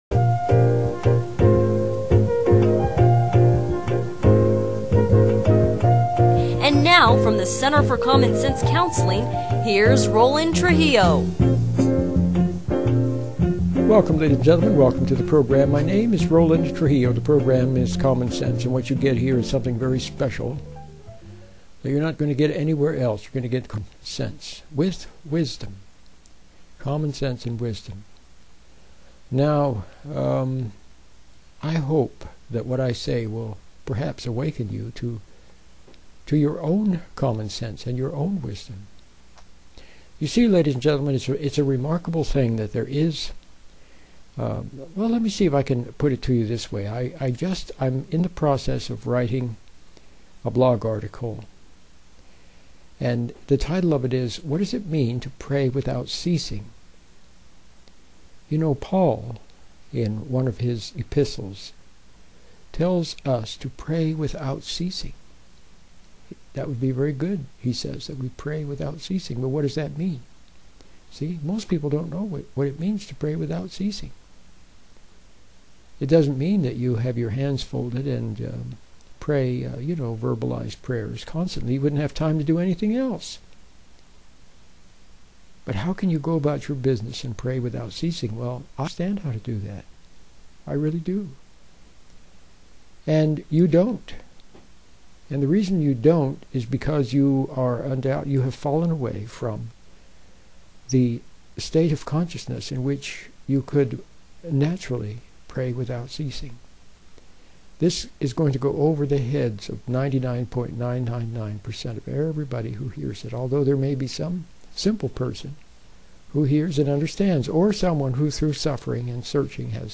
radio broadcast about praying without ceasing